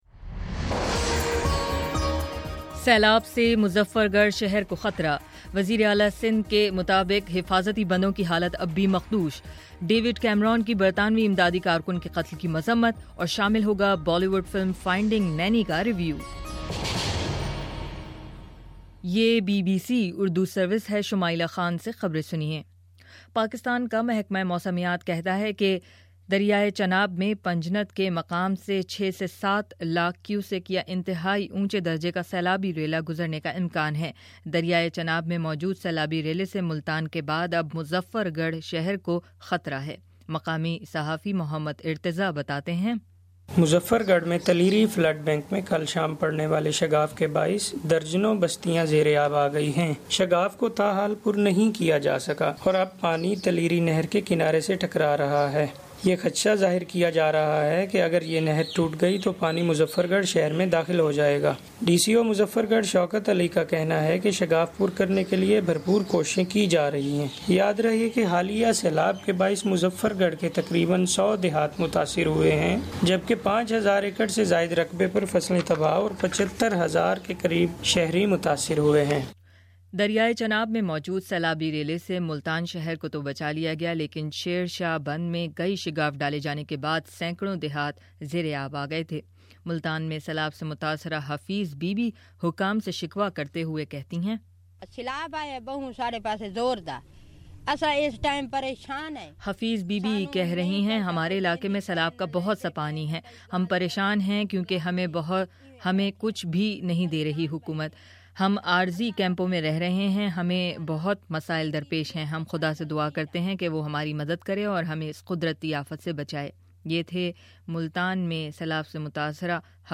اتوار14 ستمبر: صبح نو بجے کا نیوز بارہبُلیٹن